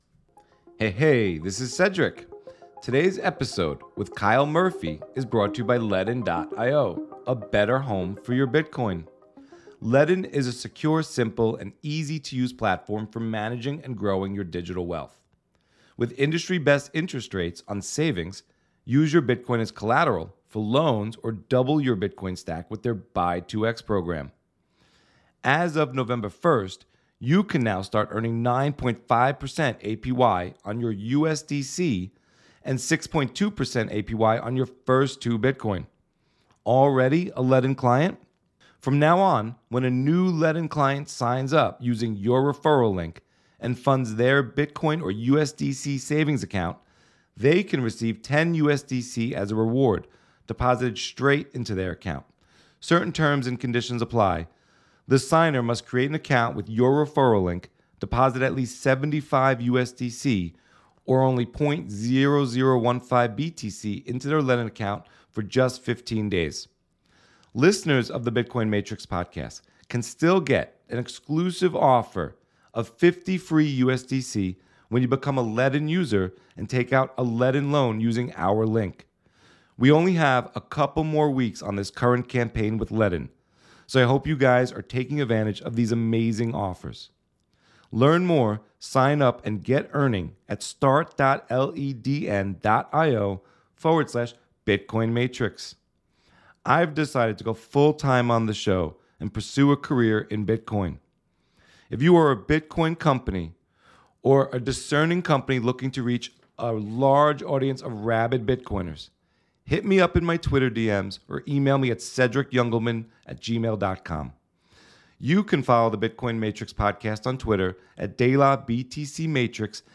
Podcasts and Videos by Bitcoin Maxis running Nodes